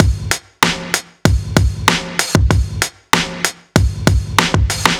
Track 14 - Drum Break 06.wav